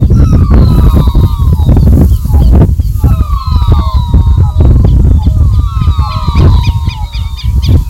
Ash-colored Cuckoo (Coccycua cinerea)
Detailed location: Camino arroyo Ñancay
Condition: Wild
Certainty: Photographed, Recorded vocal